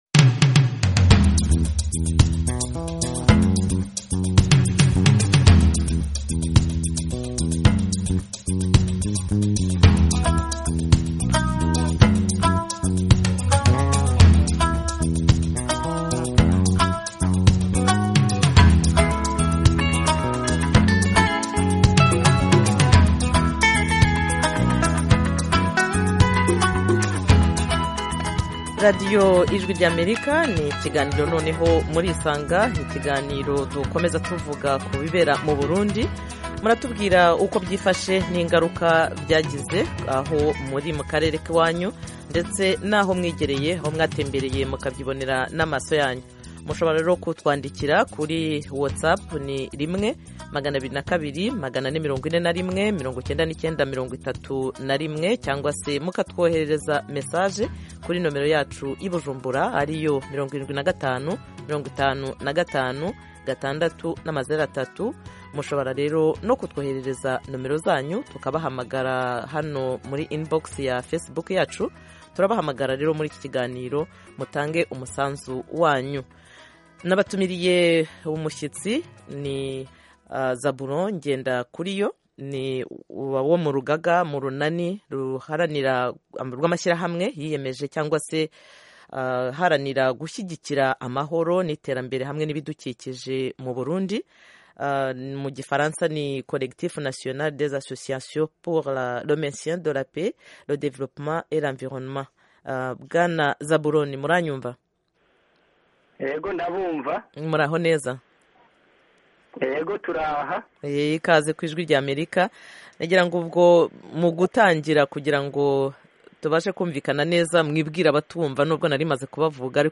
call-in show